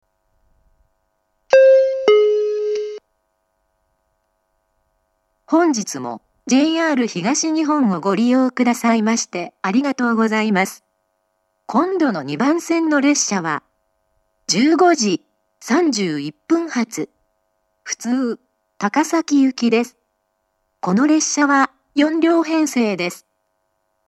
２番線次発放送 15:31発普通高崎行（４両）の放送です。